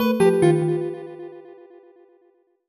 jingle_chime_18_negative.wav